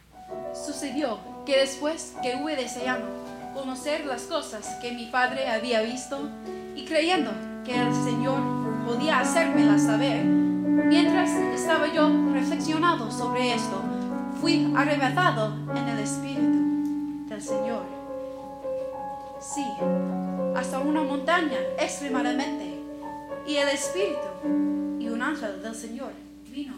Soprano and Piano